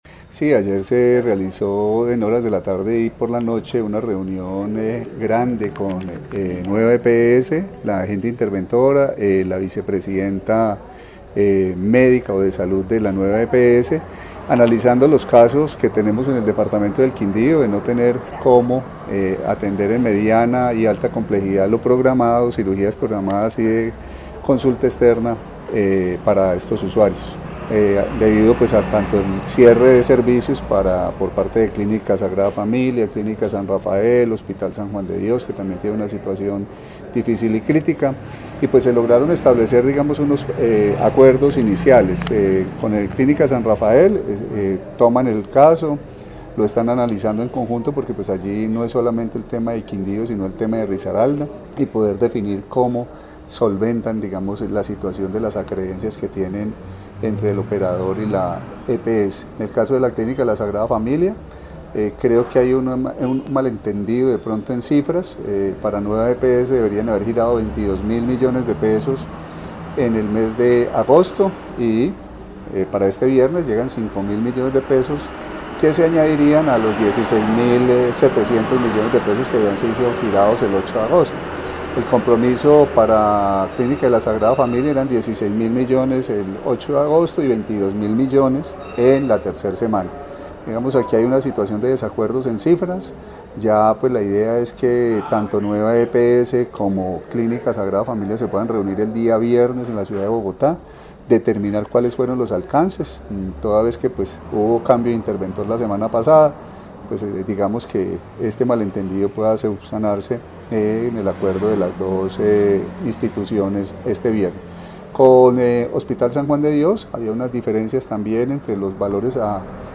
Carlos Alberto Gómez, secretario de salud del Quindío